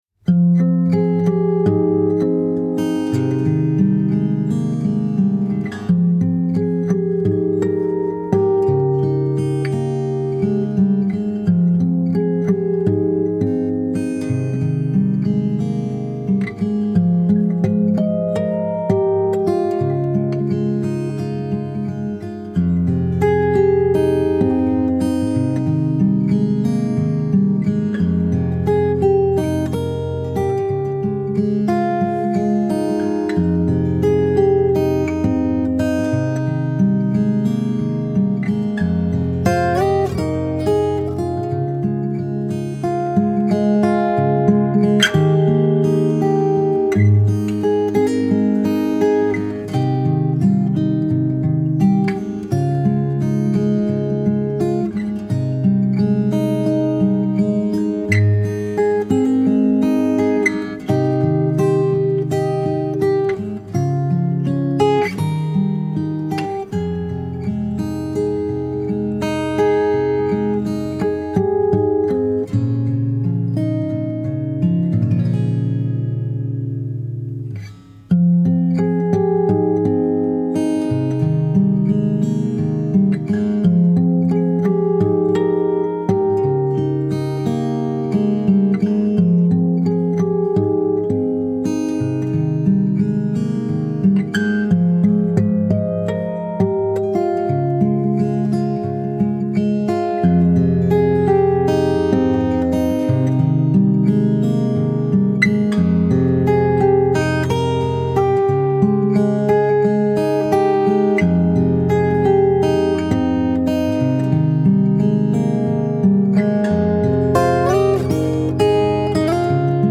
سبک آرامش بخش , گیتار , موسیقی بی کلام